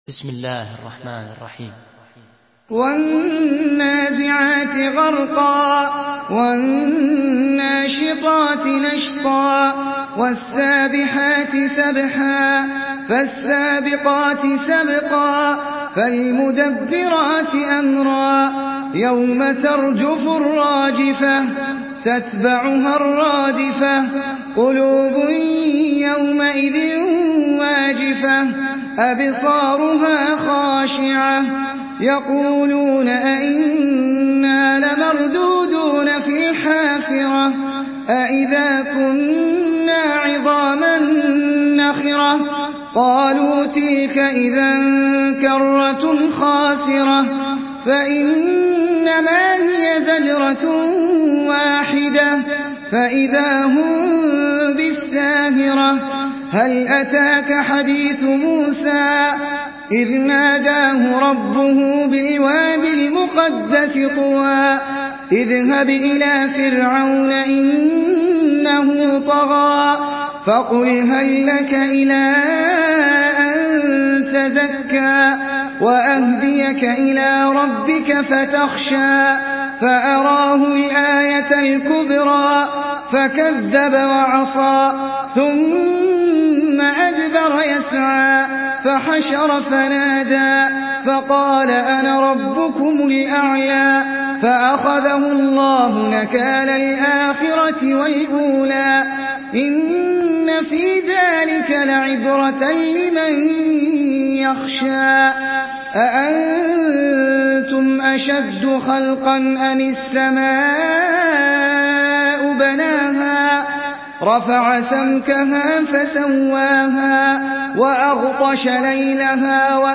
سورة النازعات MP3 بصوت أحمد العجمي برواية حفص
مرتل